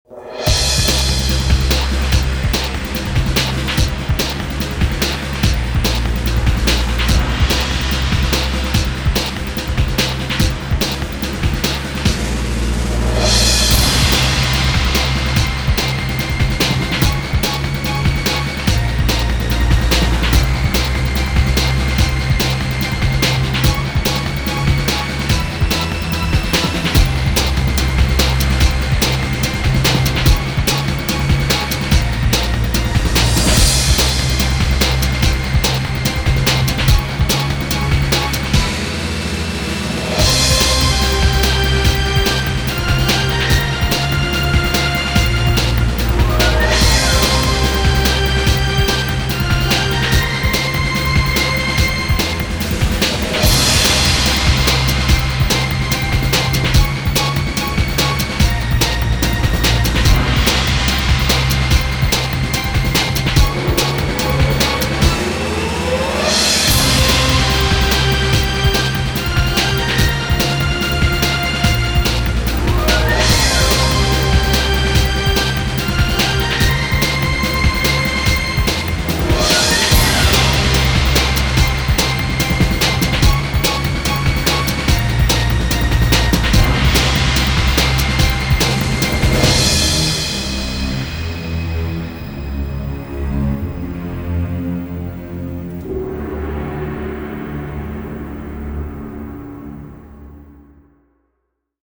Science-Fiction Thèmes Orchestraux, Ambiances & Sound Design